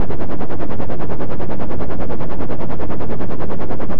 Creating a SYD Patch which simulates the sound of a HELICOPTER Back to Tutorials Menu
Variations on the Basic Helicopter Patch: Lowpass Filter
A helicpoter sound can also be achieved by using the LOWPASS filter instead of the BANDPASS filter.
When using the LOWPASS filter, the helicpoter effect is achieved by modulating the CUTOFF FREQUENCY with a low frequency oscillator (LFO).
Helicopter2.aiff